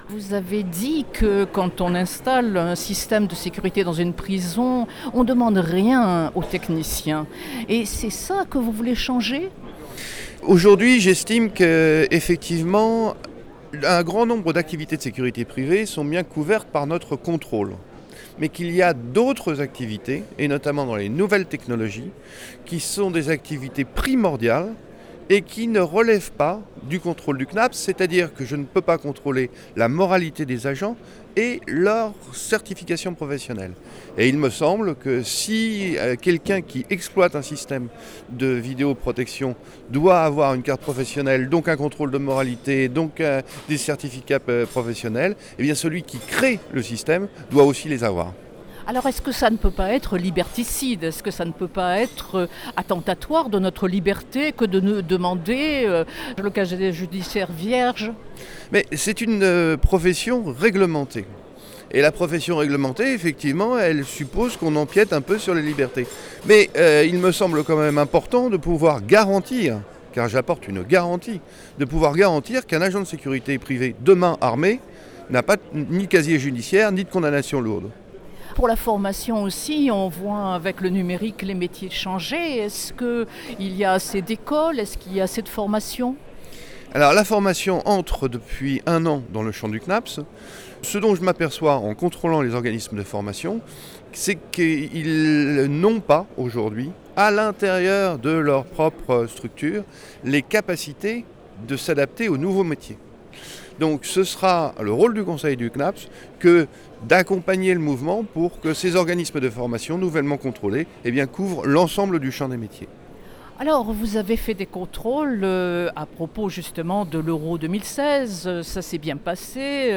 AccesSecurity, le Salon Méditerranéen de la sécurité globale, dont la cybersécurité, a ouvert ses portes, ce mercredi 29 mars pour 3 journées au Parc Chanot à Marseille.